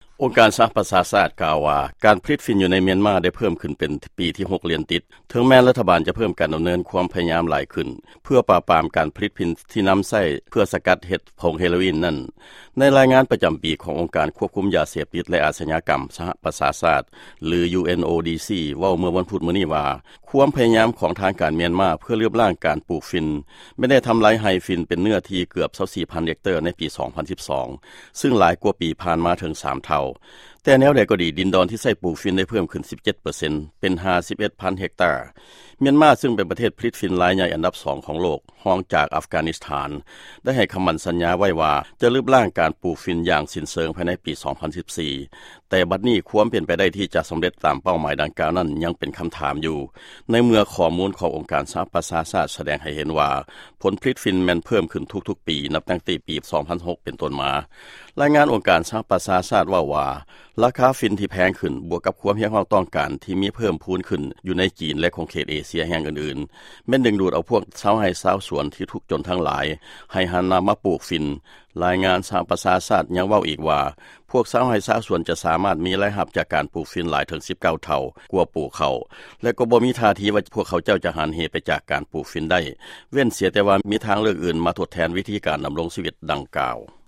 ຟັງຂ່າວກ່ຽວກັບການປູກຝິ່ນໃນມຽນມາ